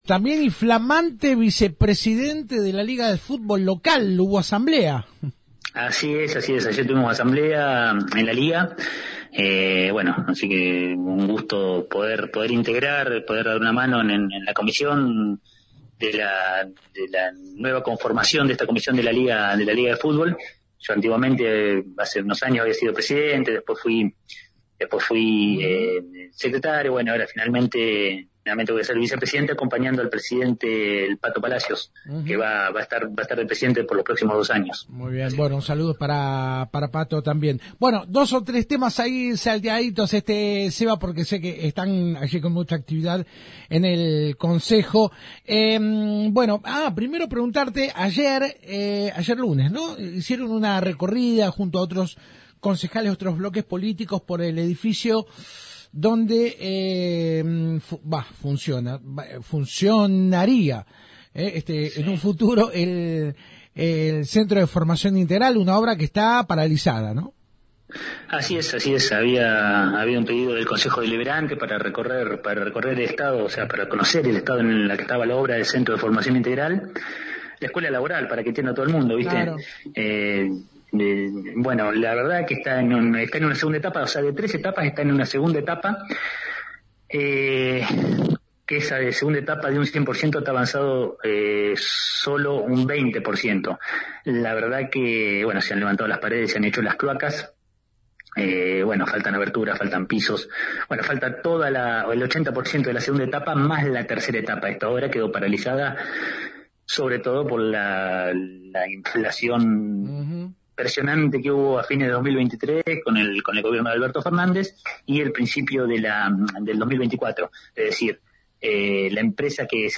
Luego de la visita de los concejales al edificio del futuro Centro de Formación Integral Nro. 1, ubicado en Avda. Carmen y Martín Fierro, la 91.5 conversó con el edil del Bloque Adelante Juntos.